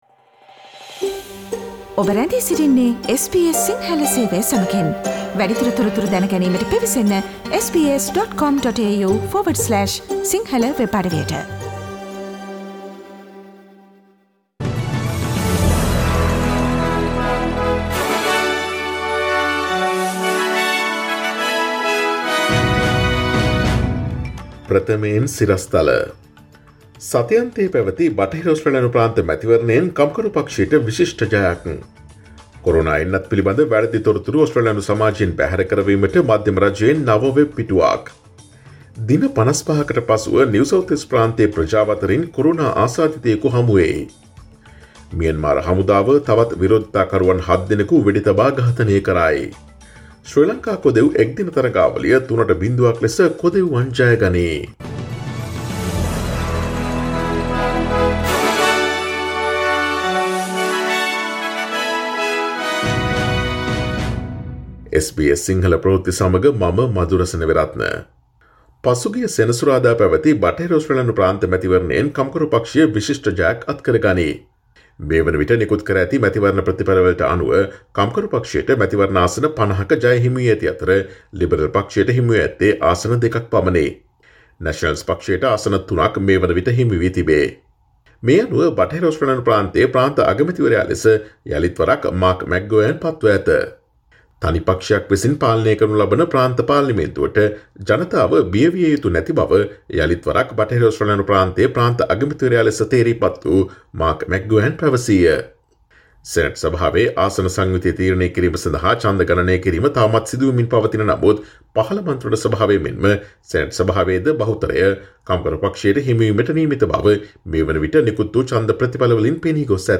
Here are the most prominent Australian and Sri Lankan news highlights from SBS Sinhala radio daily news bulletin on Monday 15 March 2021.